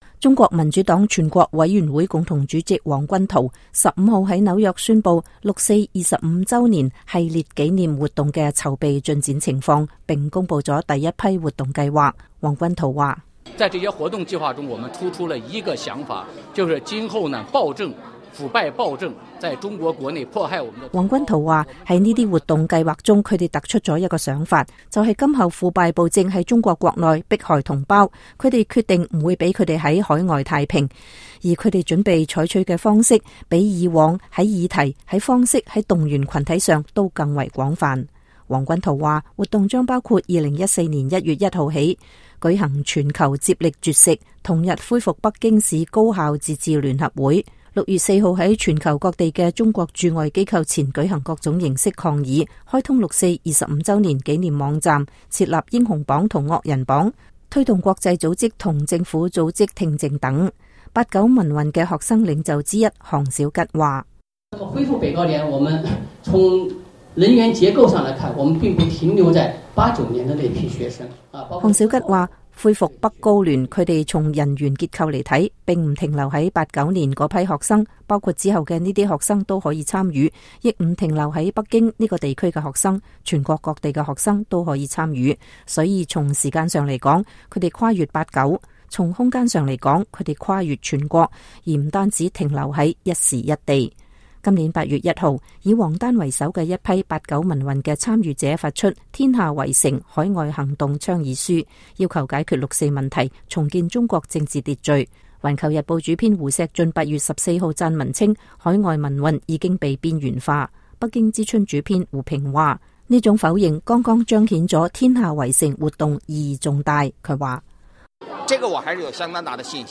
中國民主黨全國委員會共同主席王軍濤15號在紐約宣佈六四25週年系列紀念活動的籌備進展情況，並公佈了第一批活動計劃。